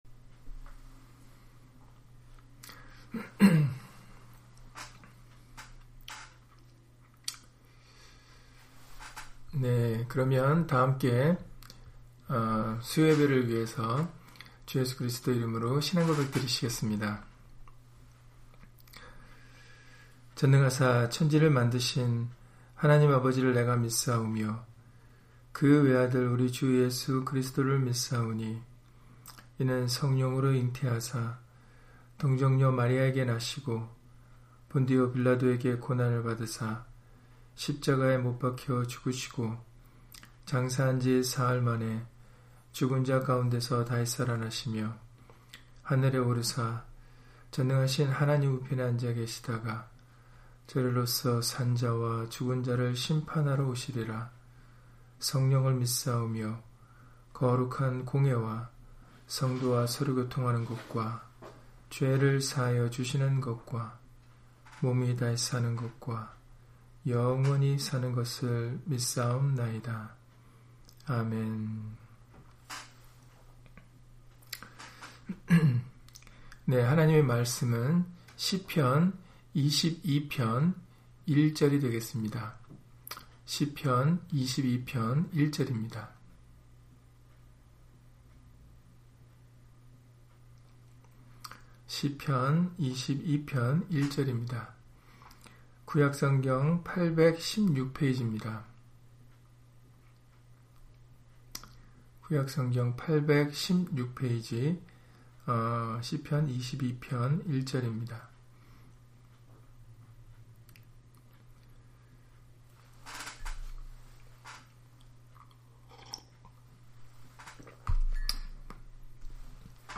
시편 22편 1절 [어찌 나를 버리셨나이까] - 주일/수요예배 설교 - 주 예수 그리스도 이름 예배당